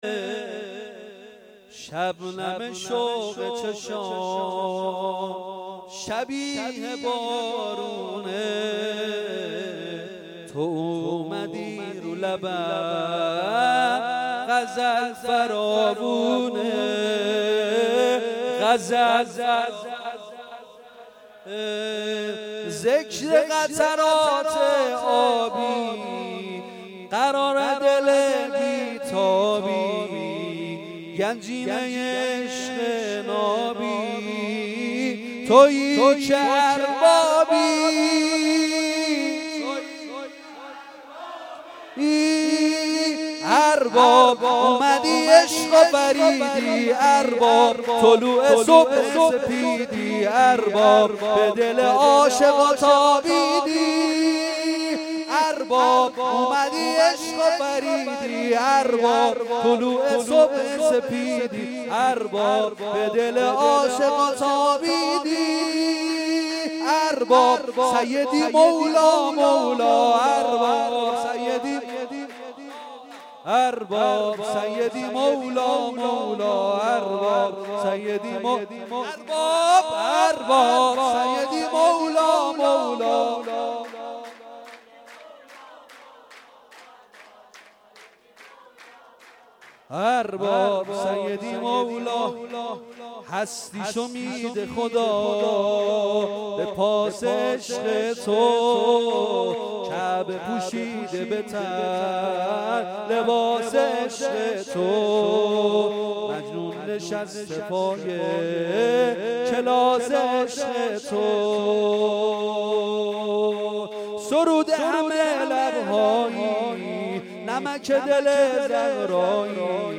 اعیاد شعبانیه